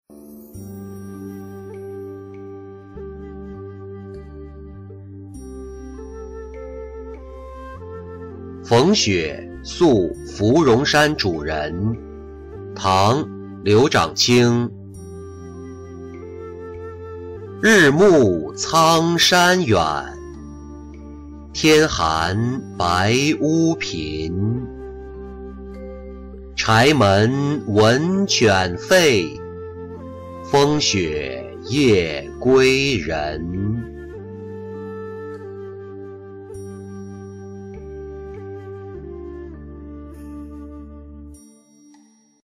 逢雪宿芙蓉山主人-音频朗读